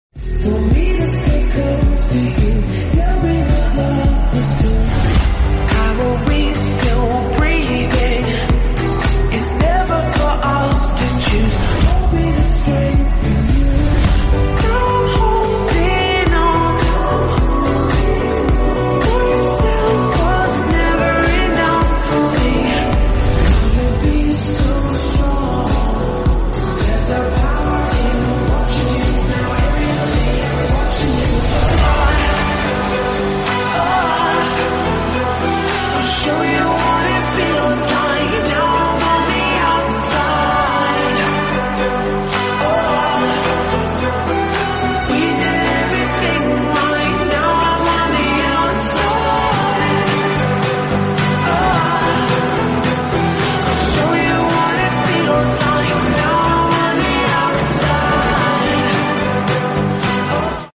#3DAudio